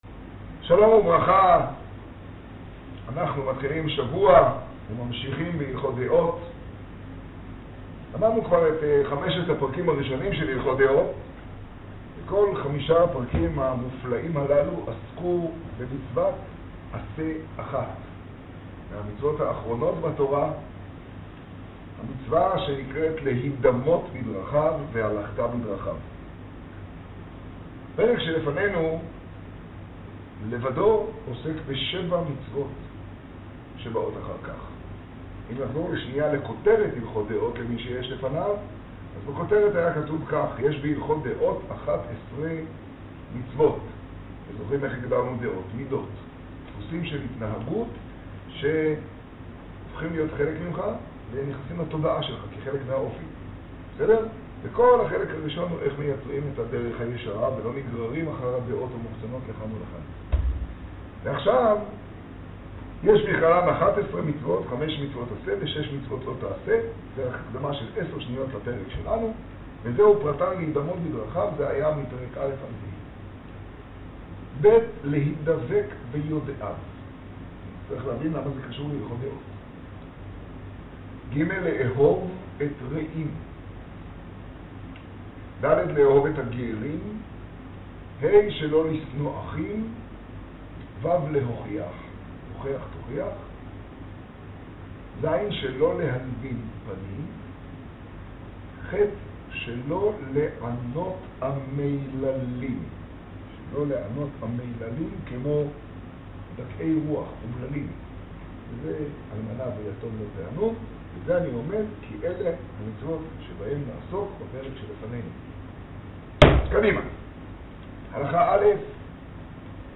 השיעור במגדל, כ טבת תשעה.